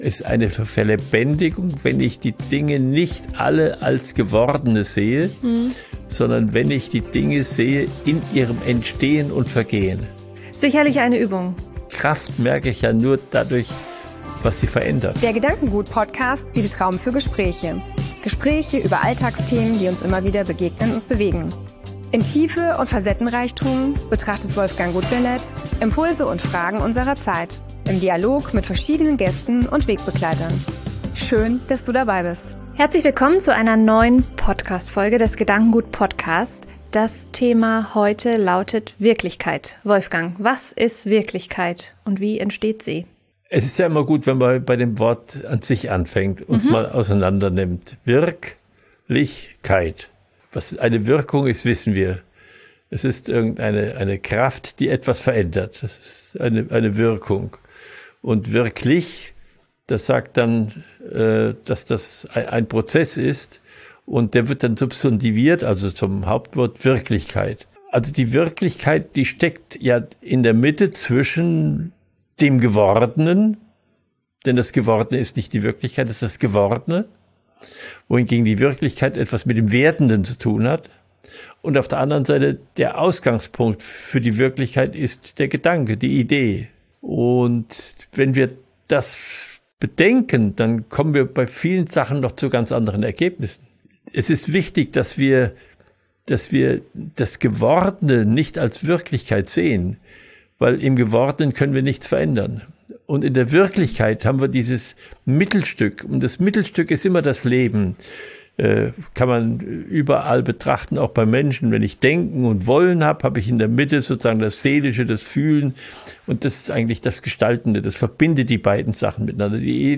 In dieser Folge sprechen wir darüber, warum die Wirklichkeit nicht das ist, was wir sehen – sondern das, was sich zwischen Idee und Tat entfaltet. Ein Gespräch über Prozesse statt Zustände, über den lebendigen Blick auf das Leben, über Bio, Forschung, Goethe und die Frage, wie wir das Wesen der Dinge wirklich erfassen.